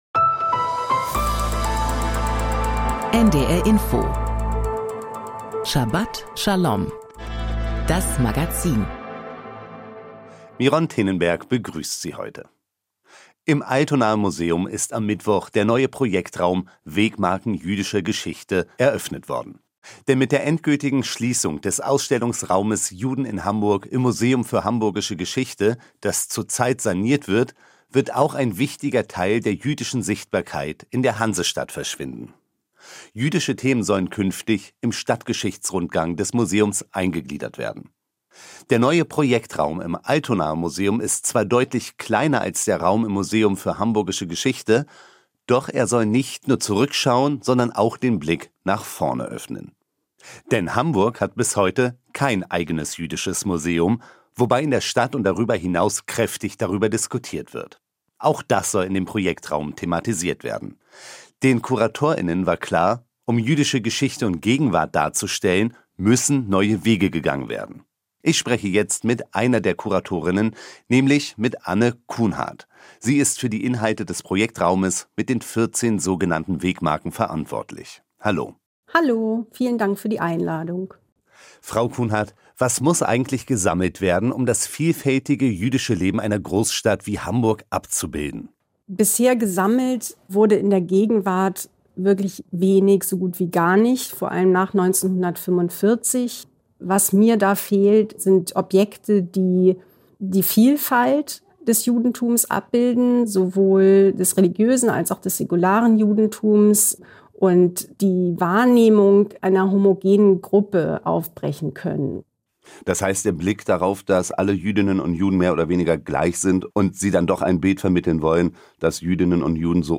Thora-Auslegung